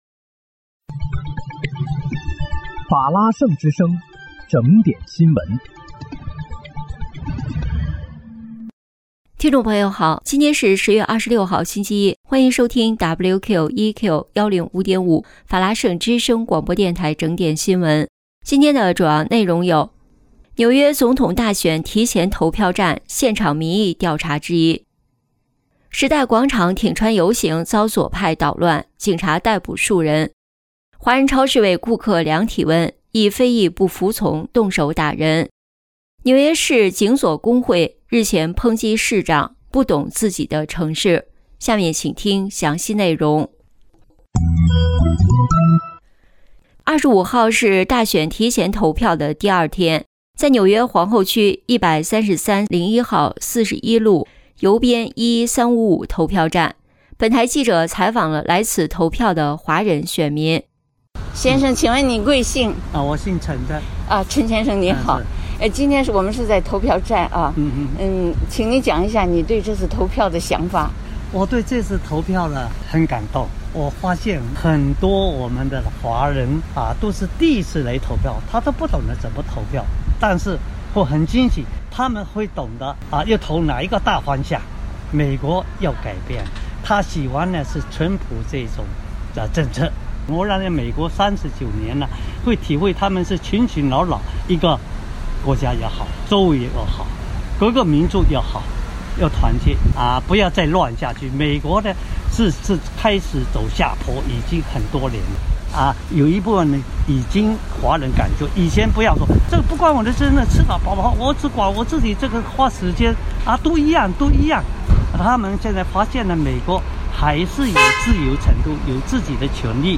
10月26日（星期一）纽约整点新闻